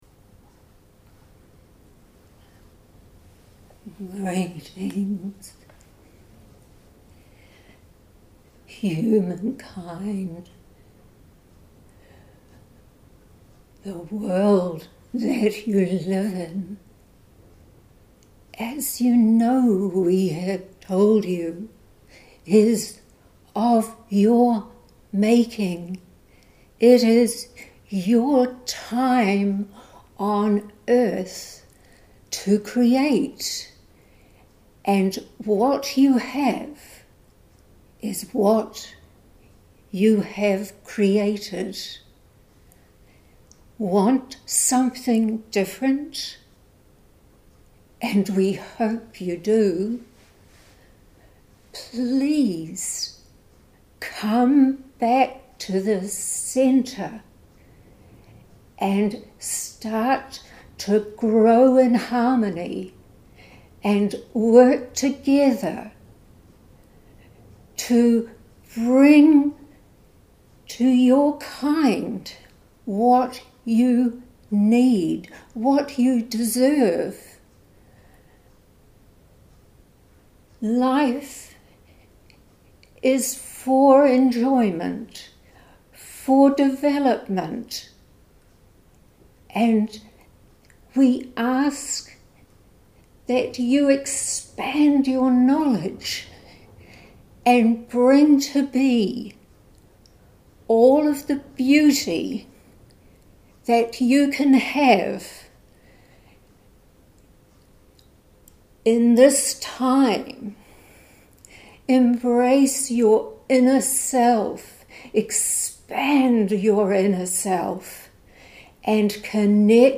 Posted in Audio recording, Metaphysical, Spirituality, Trance medium